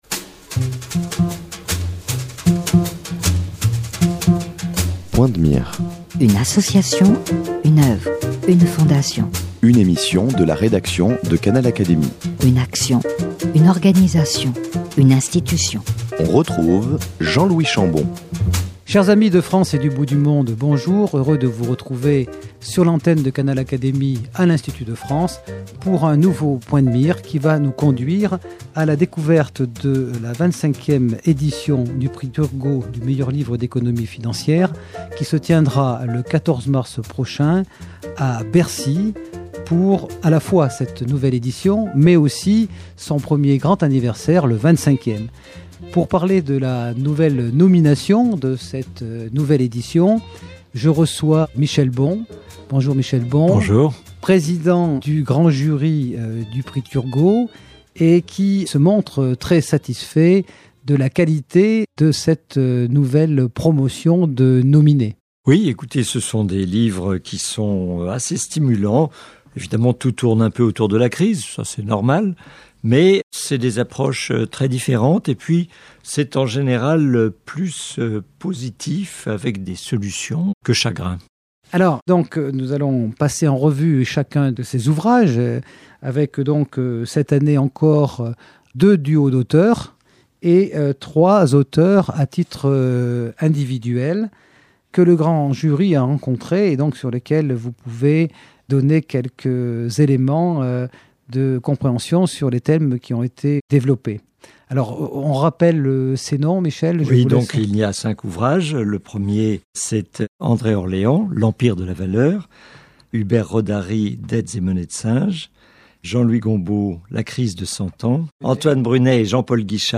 Réponses dans cet entretien.